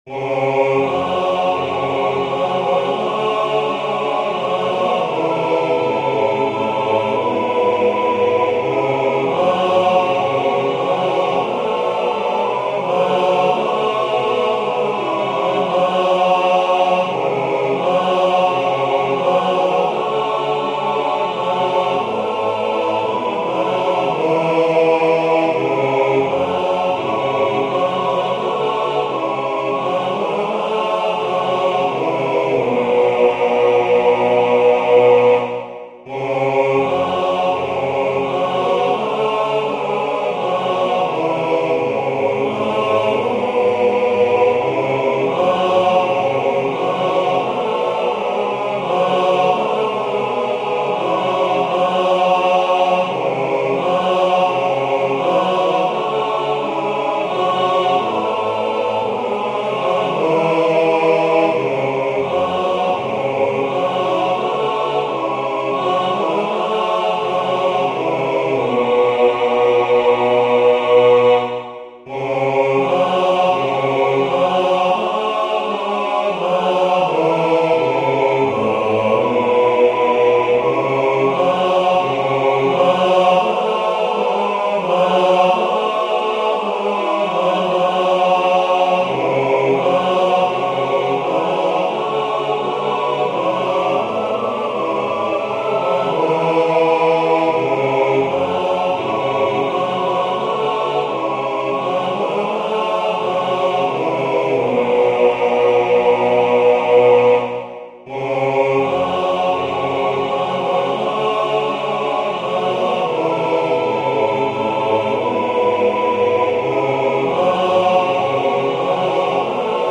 I’m imagining this not so much for full choir as for, say, an octet accompanying a male soloist (though the soprano and bass parts get turns joining on the melody as well).
computer-generated mp3, which isn’t great, but is something at least.